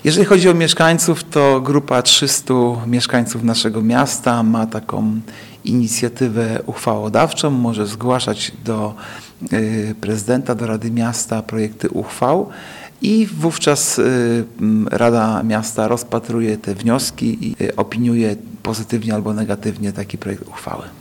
Taka petycja jeżeli ma być wiążąca, musi być podpisana fizycznie przez obywateli, a w wypadku internetu musi być to podpis kwalifikowany. – tłumaczy Artur Urbański – zastępca prezydenta miasta